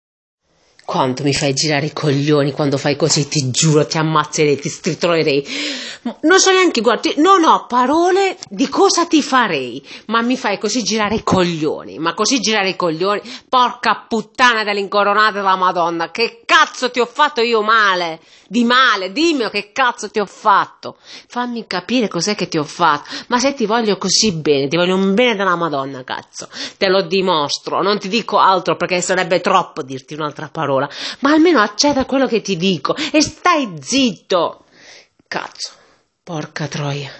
suoneria per cell donna incazzata suoneria per sms